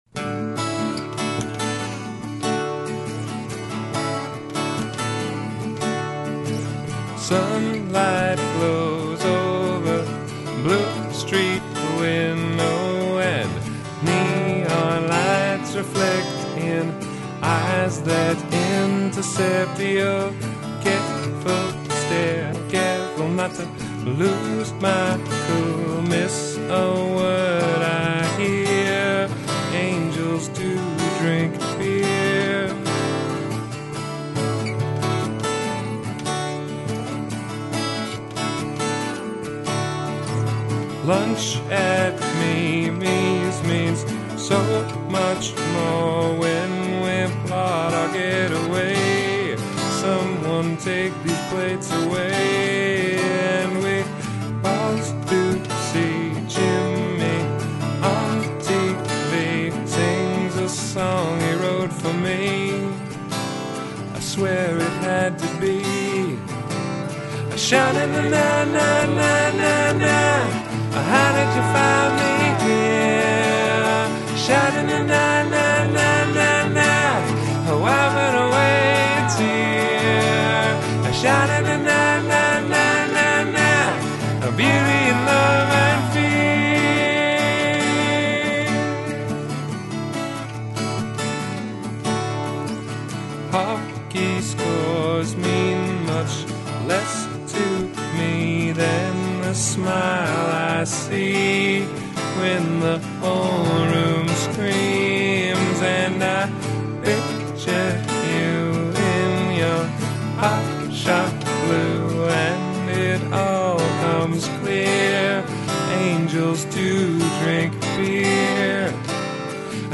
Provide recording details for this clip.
Here are some of the rough demo's for the album.